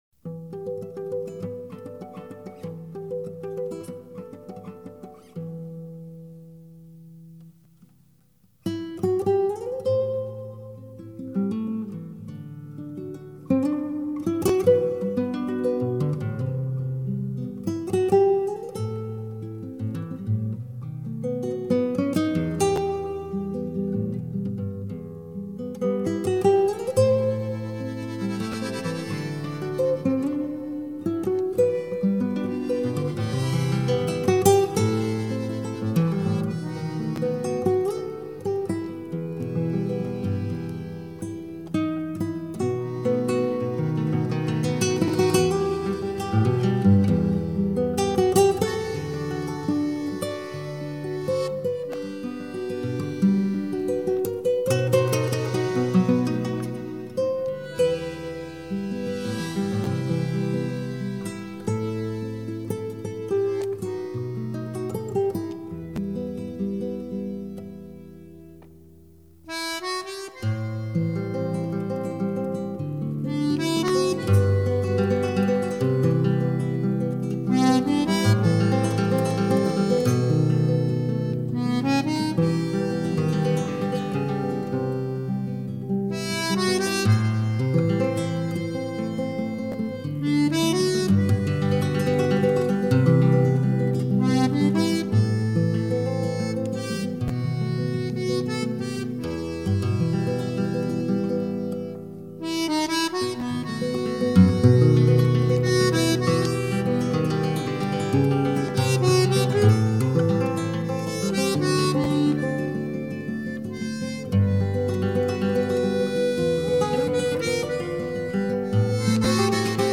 Гитарные пьесы
Саунд светлый и радостный, музыка лёгкая и доступная.